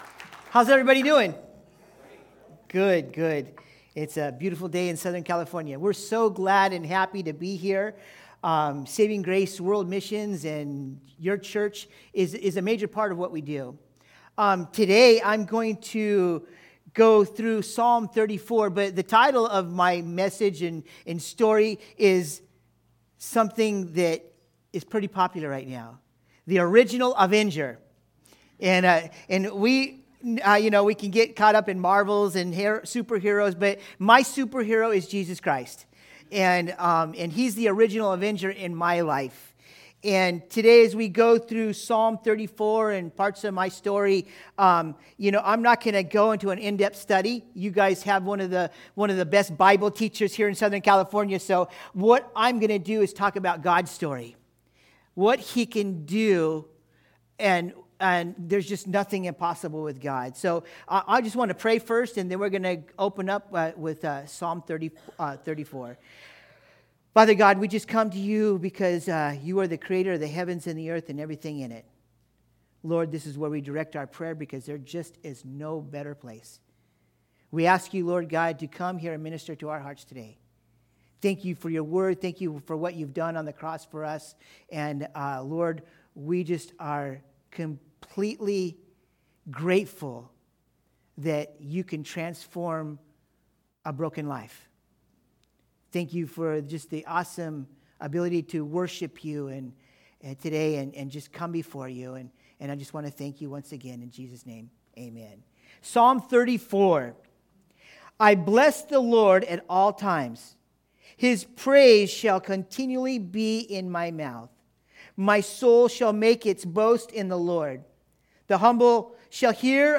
Topics: Guest Speakers